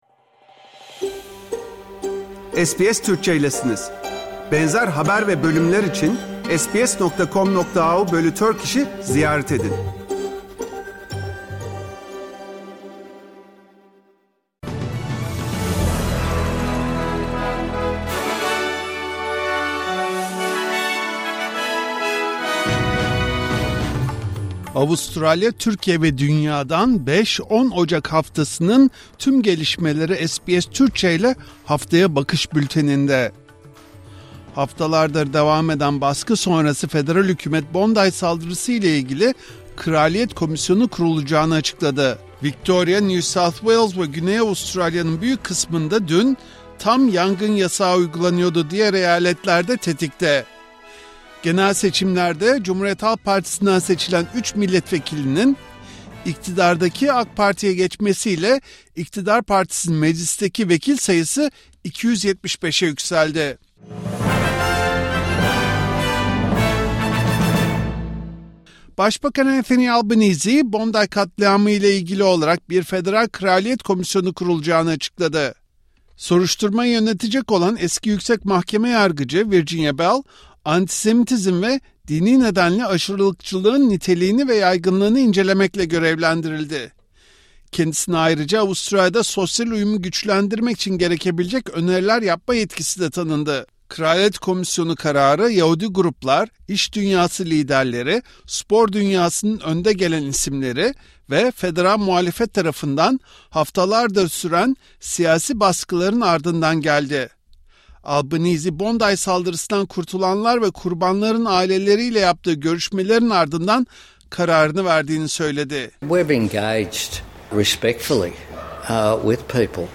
Avustralya, Türkiye ve dünyadan 5 – 10 Ocak 2026 haftasının tüm gelişmeleri SBS Türkçe ile Haftaya Bakış bülteninde.